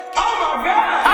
TS - CHANT (14).wav